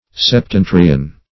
septentrion - definition of septentrion - synonyms, pronunciation, spelling from Free Dictionary
Septentrion \Sep*ten"tri*on\, n. [L. septentrio the northern